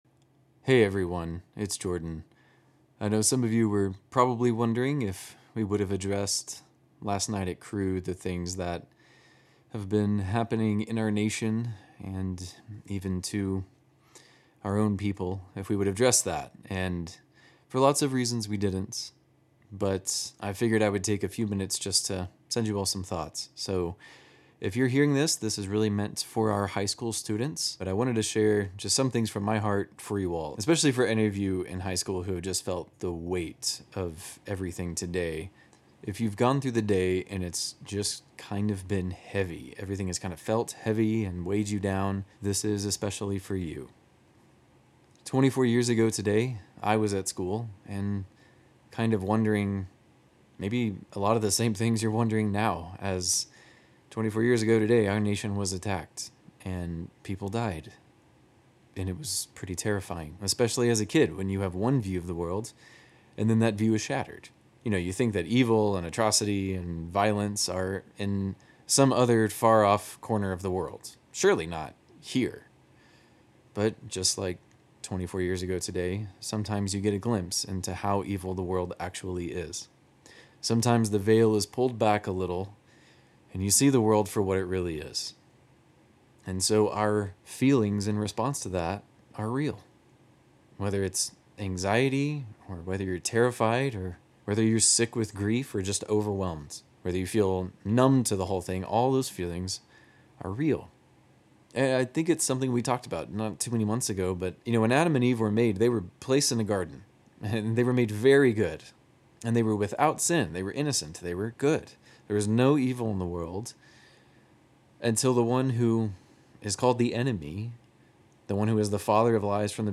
A message for High School students on Sep 11, 2025.